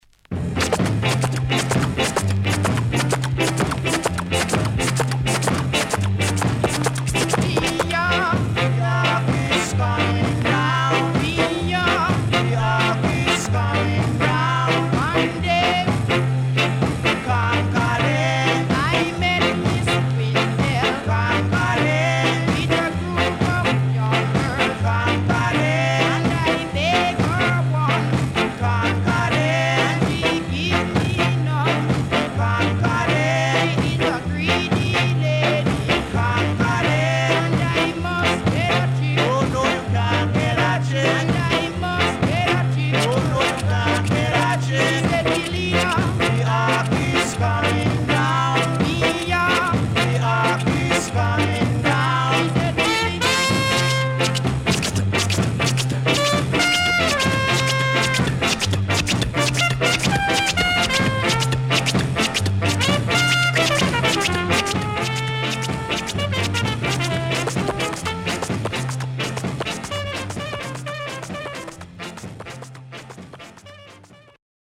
Good Ska Vocal
SIDE A:所々チリノイズがあり、少しプチノイズ入ります。